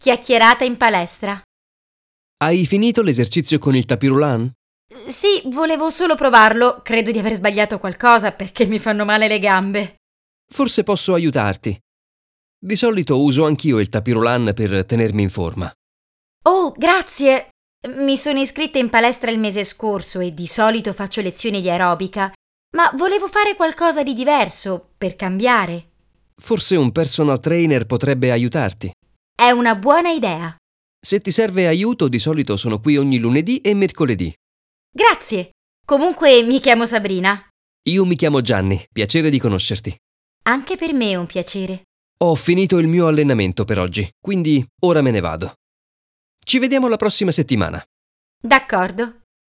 Chiacchierata in palestra – Beszélgetés a konditeremben - 5 Perc Olasz - Mindenkinek